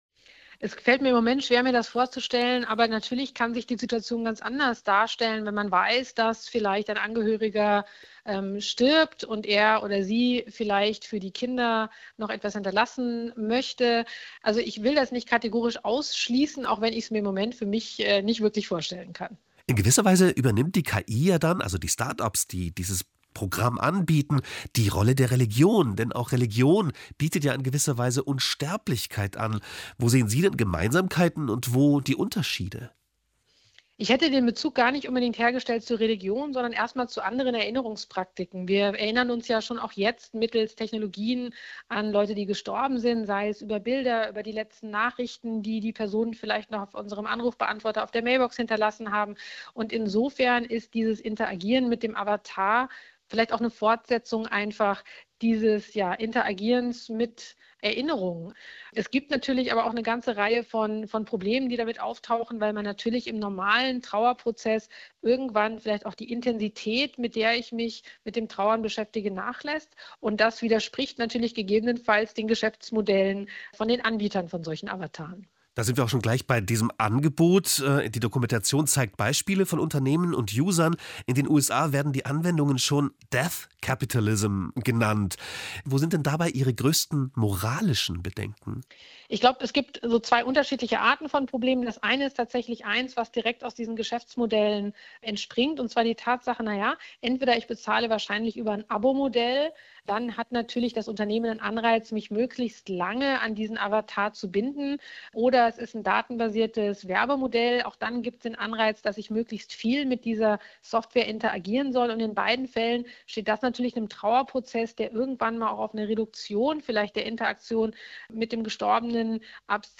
Audiodatei (mp3) des Interviews